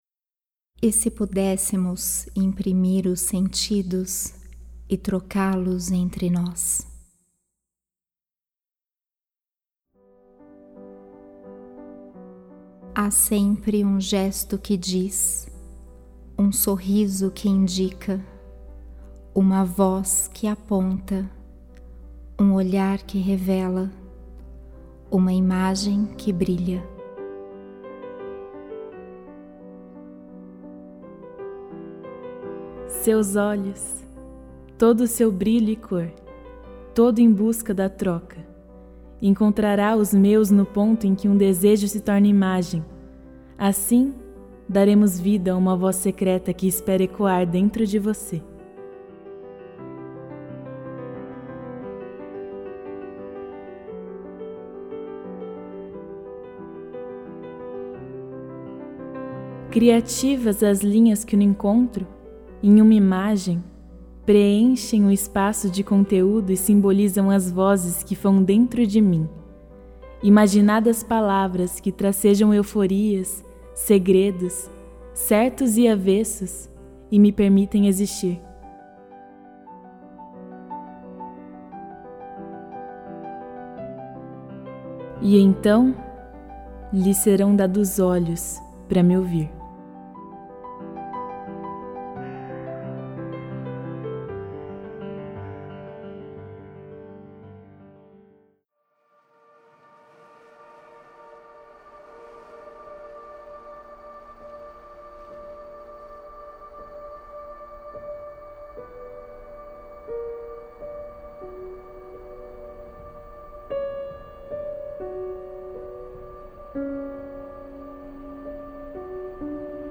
O áudio é uma parte da obra, agora disponibilizado neste post. Poesia para ouvir e sensibilidade para tocar.